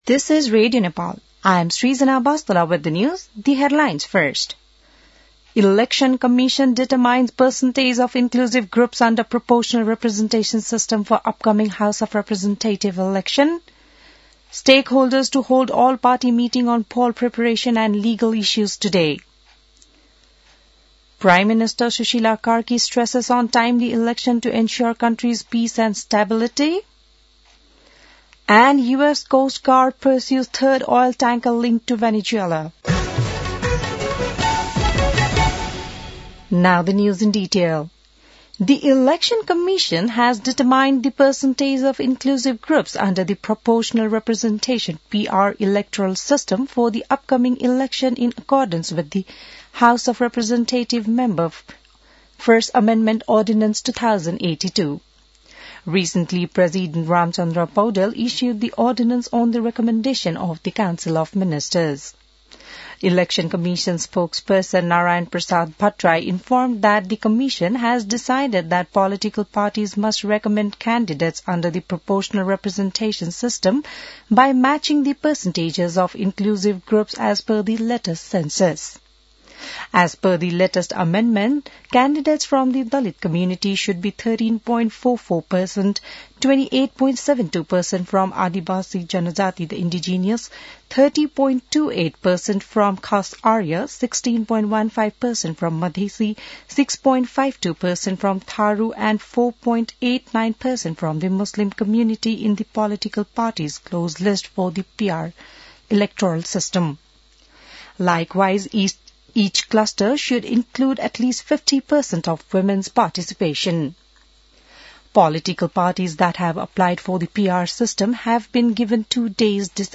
बिहान ८ बजेको अङ्ग्रेजी समाचार : ७ पुष , २०८२